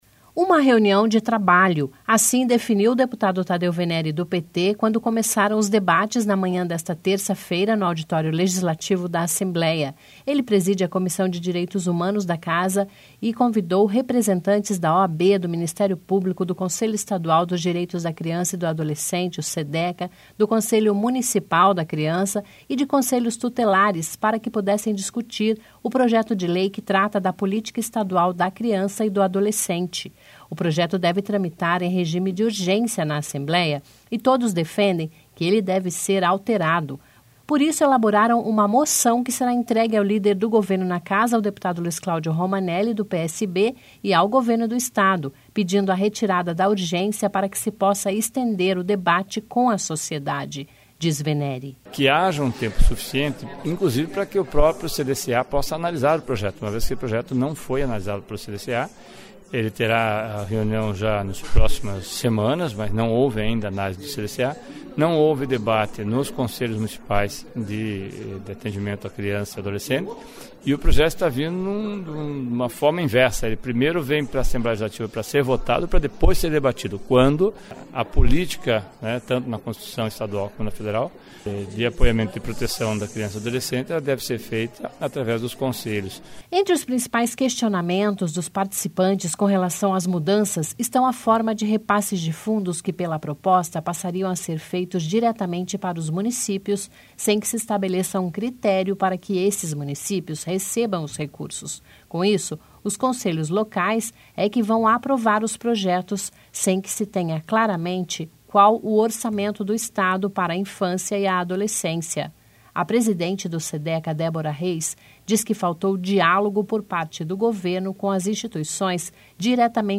(Descrição do áudio)) Uma reunião de trabalho.